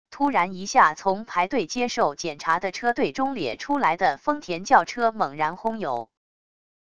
突然一下从排队接受检查的车队中咧出来的丰田轿车猛然轰油wav音频